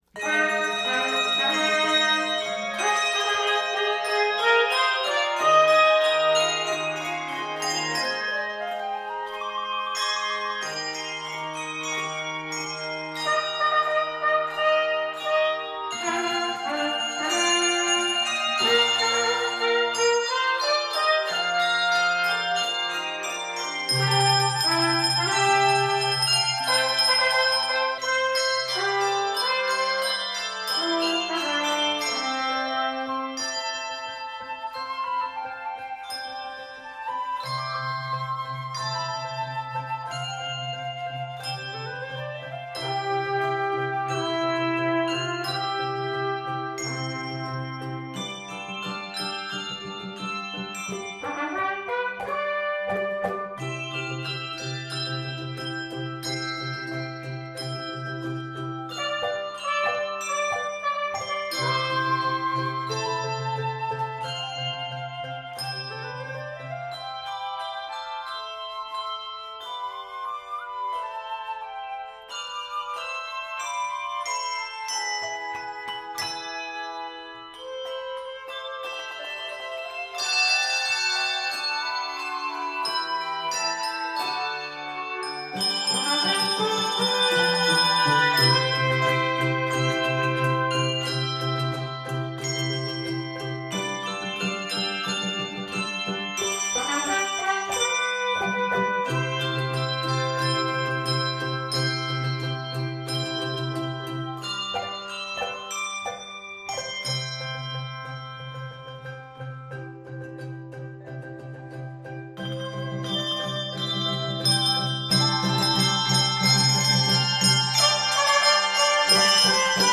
This huge sounding selection